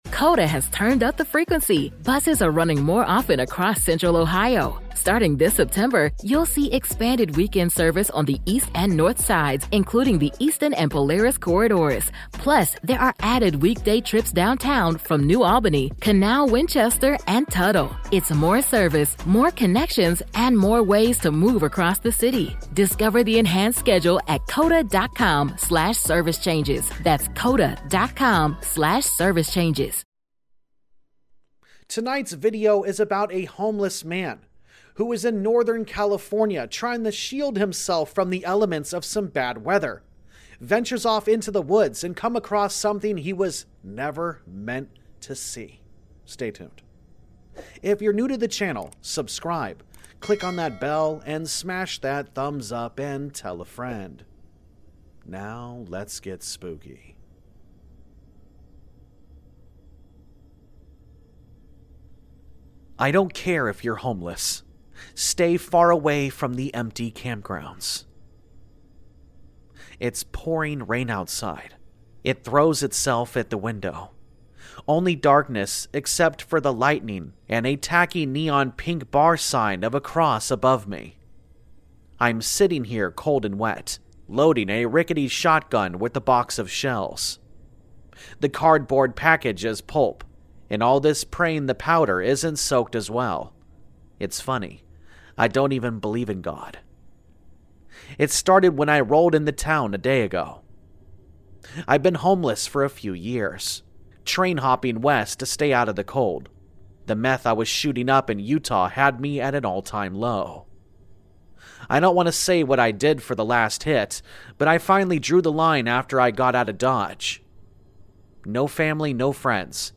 Listen & download your favorite scary stories: